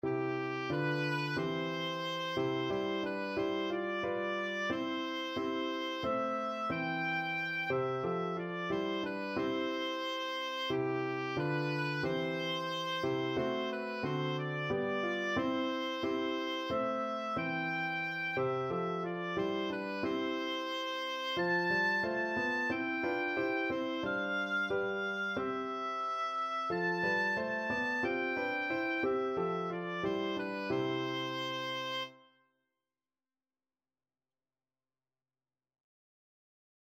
Oboe
C major (Sounding Pitch) (View more C major Music for Oboe )
4/4 (View more 4/4 Music)
Traditional (View more Traditional Oboe Music)